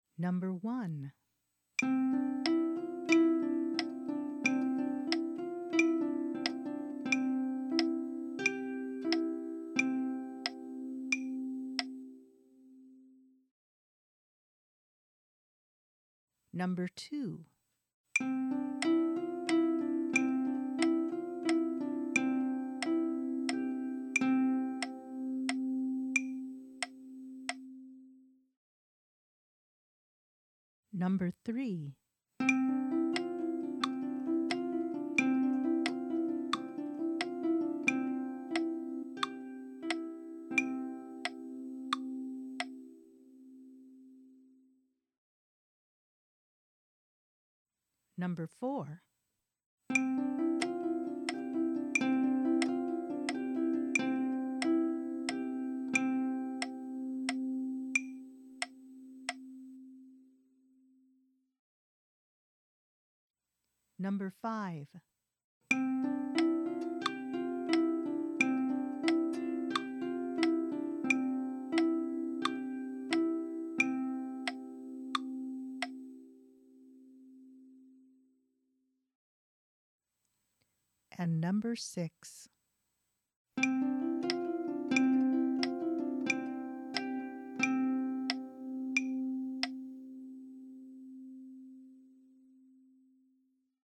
And another hint is that I'll be playing against a metronome set to the underlying stress for each.
meter.mp3